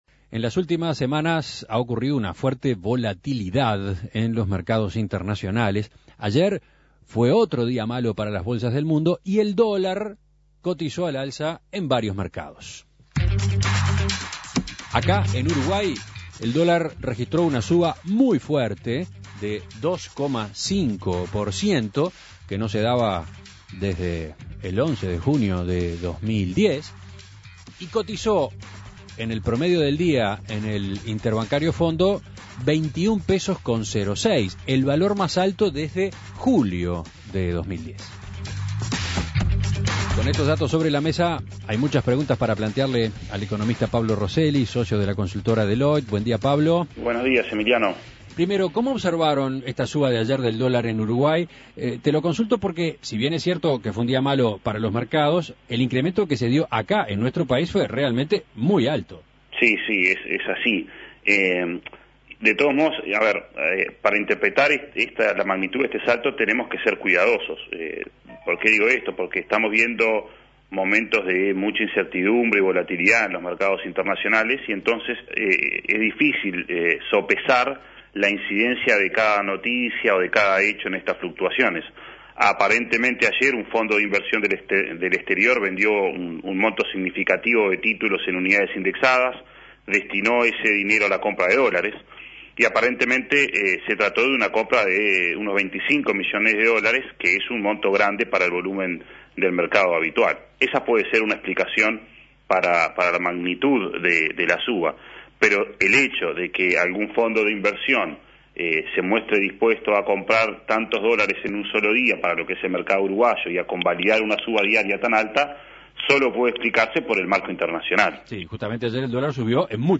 Análisis Económico Fuerte suba del dólar en Uruguay